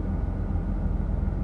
idle.ogg